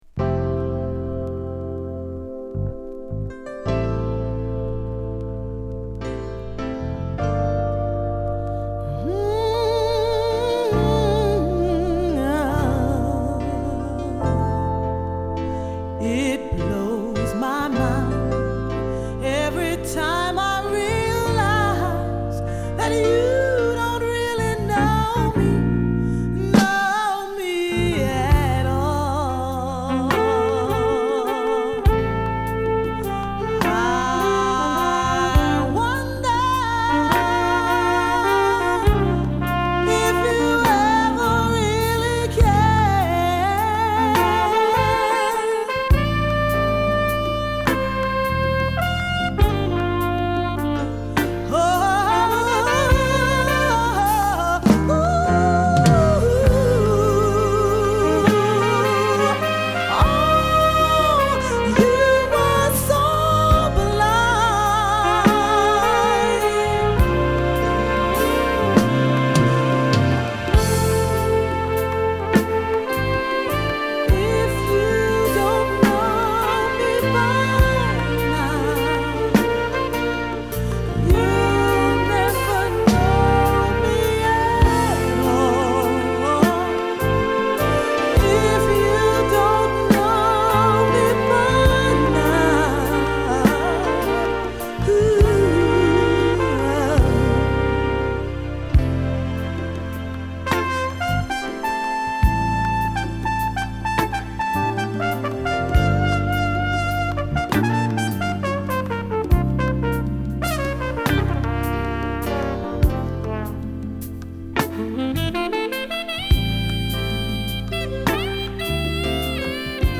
南アフリカ出身のフリューゲルホルン／コルネット奏者